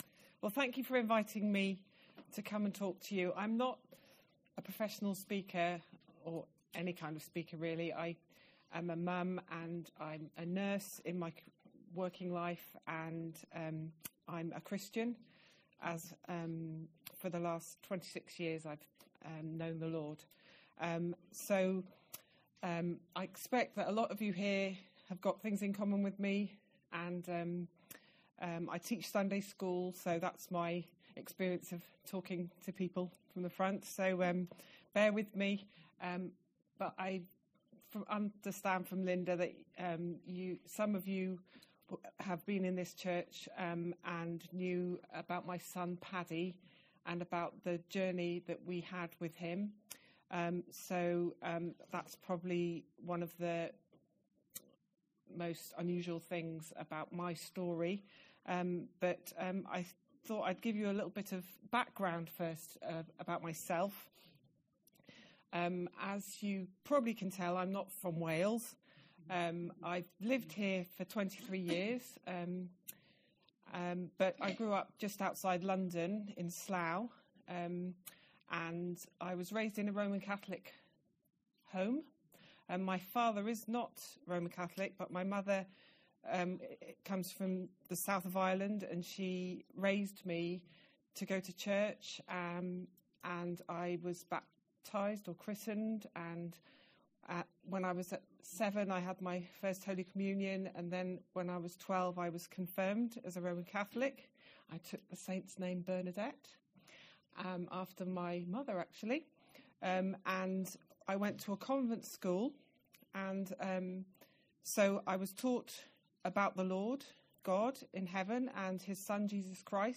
Women from our church congregation and guests met to share a buffet breakfast